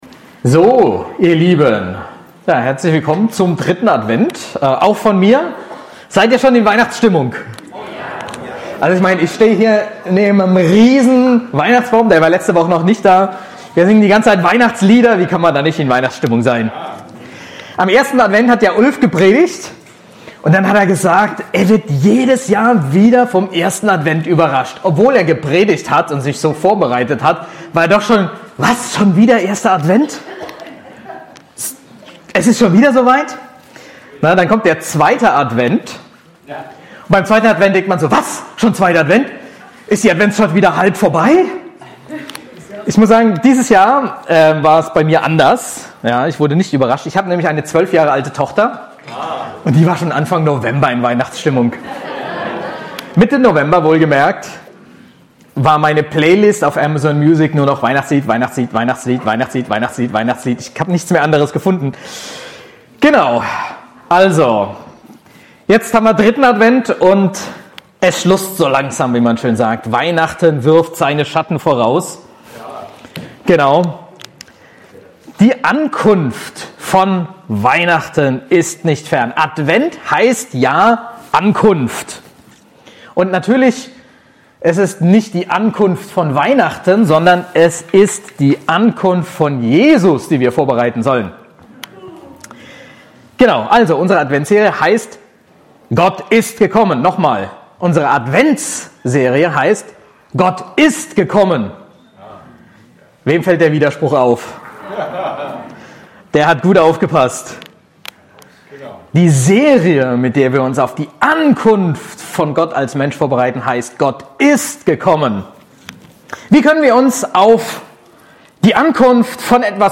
Gott kommt zu den Menschen ~ BGC Predigten Gottesdienst Podcast